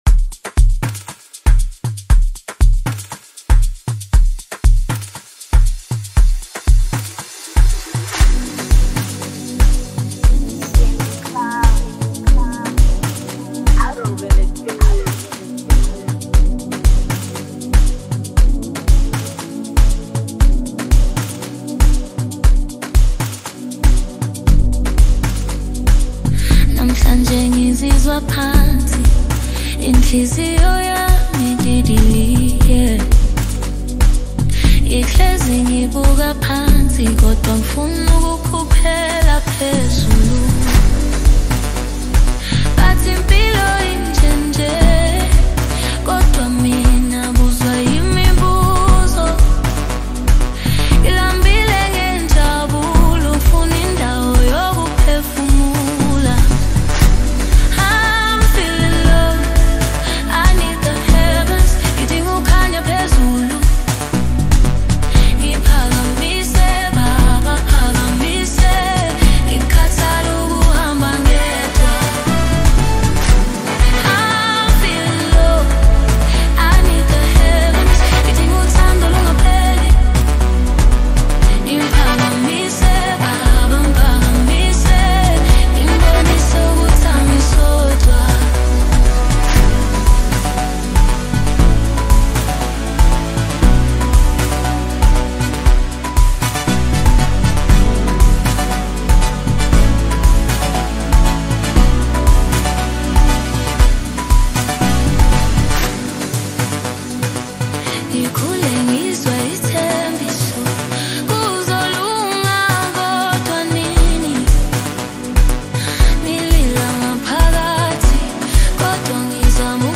Afro 3 step
South African singer-songwriter